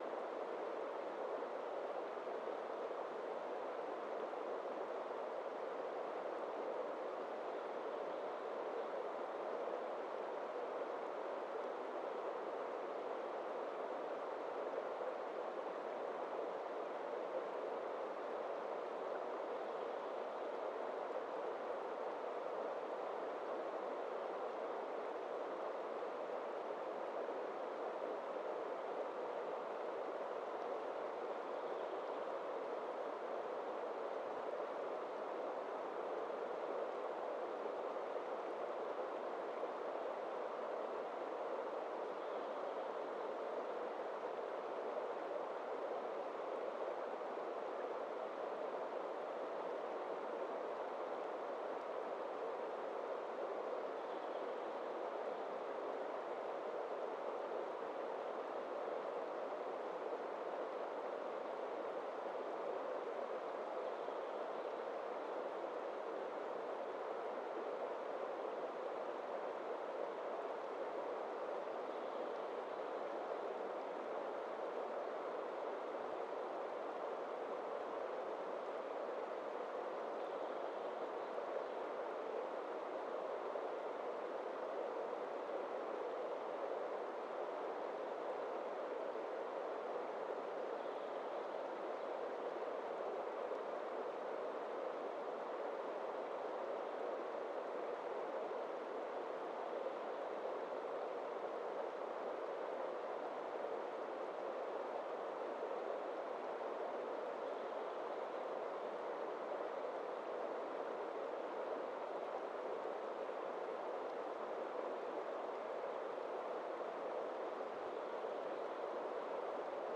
Quellrauschen500.mp3